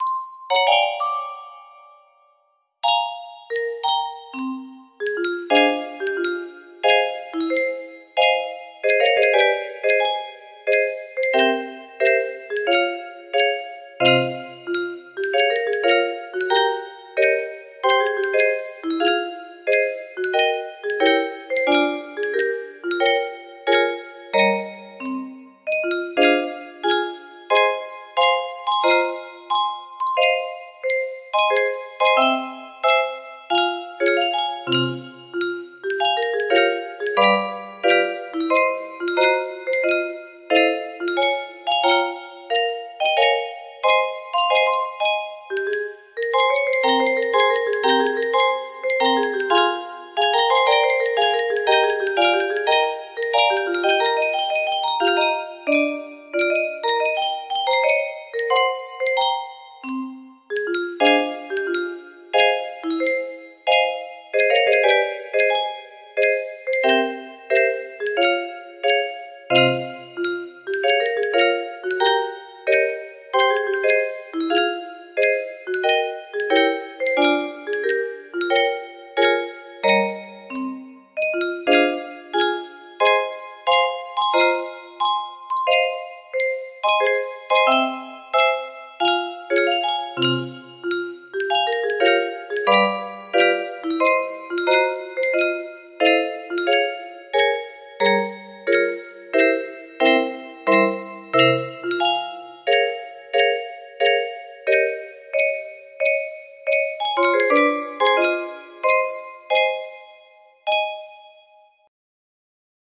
specially adapted for 30 notes